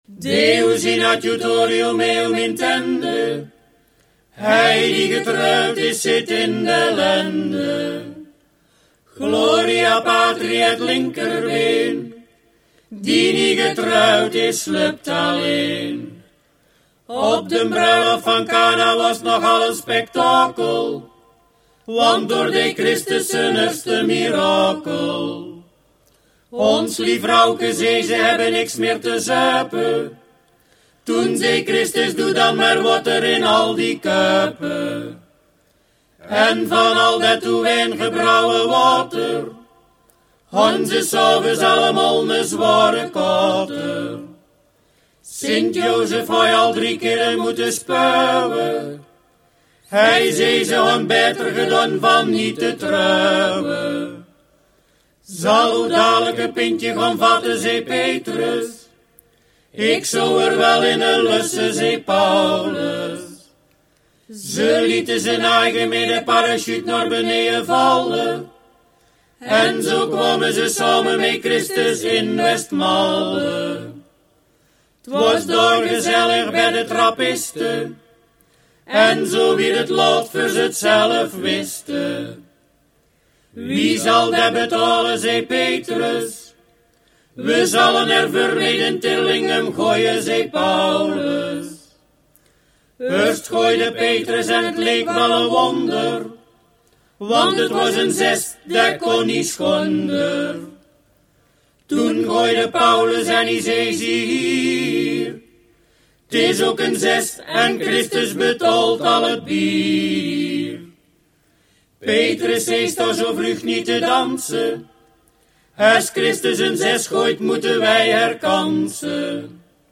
driestemmige zang